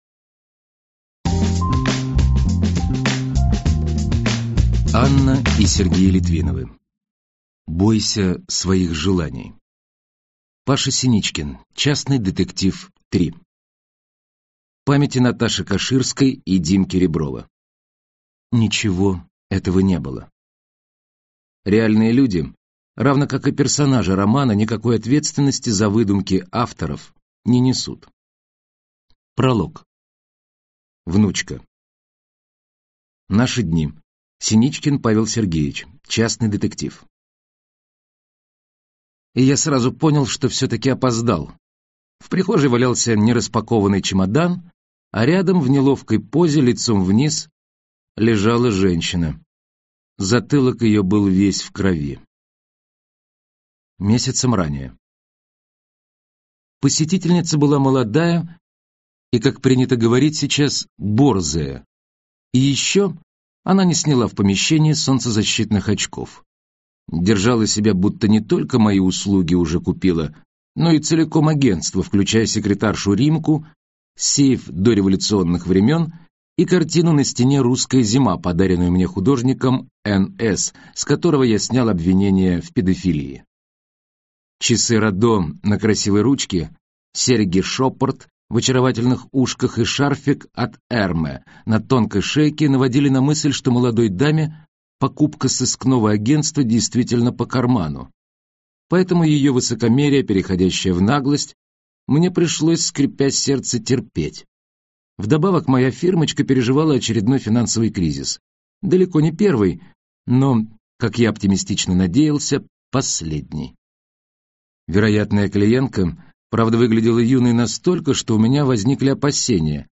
Аудиокнига Бойся своих желаний. Сборник | Библиотека аудиокниг